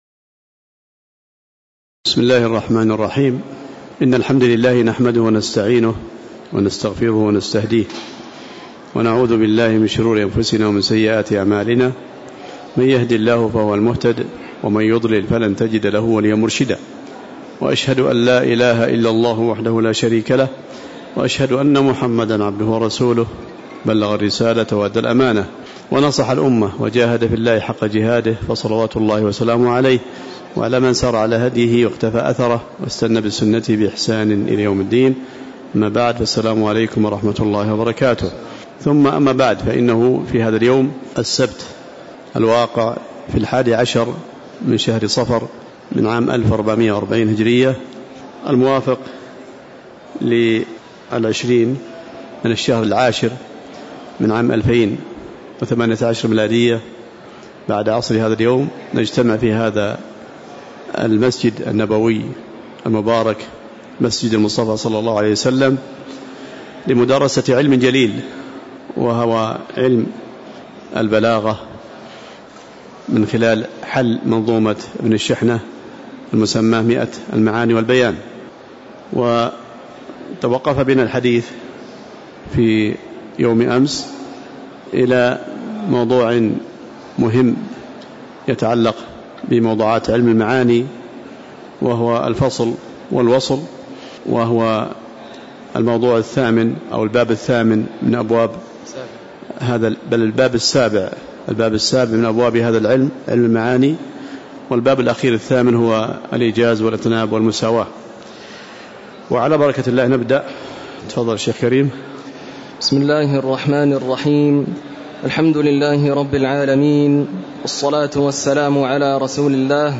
تاريخ النشر ١١ صفر ١٤٤٠ هـ المكان: المسجد النبوي الشيخ